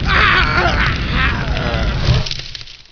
firedeth.wav